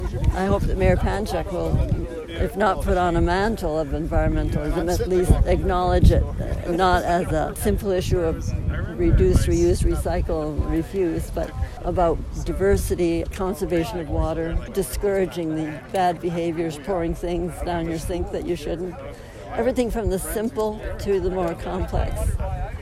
During her remarks on Monday, Langer stressed the important role that local government can play in keeping environmentalism top of mind for residents.